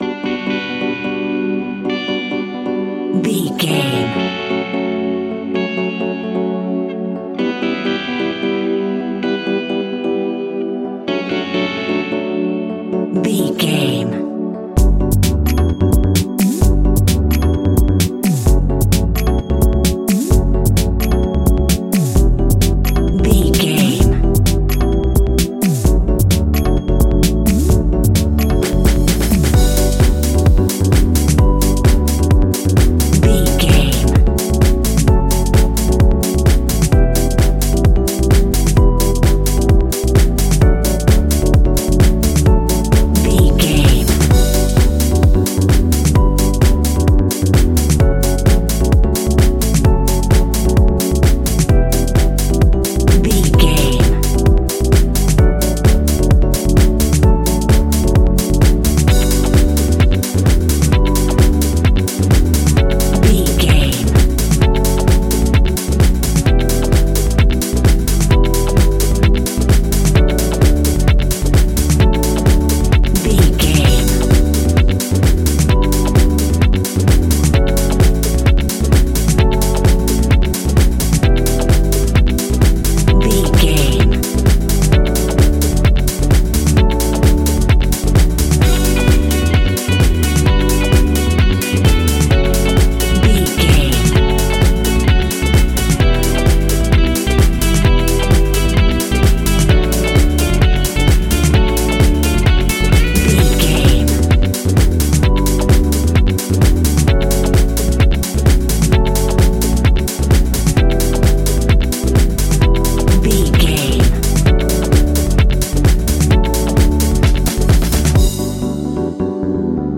Aeolian/Minor
uplifting
energetic
bouncy
synthesiser
electric piano
bass guitar
saxophone
drum machine
nu disco
groovy